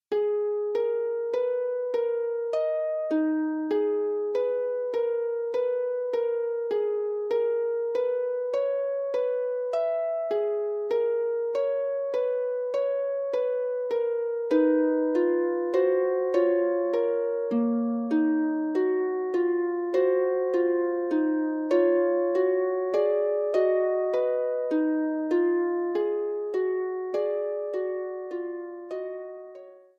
for solo pedal harp.
Presented in A-flat minor.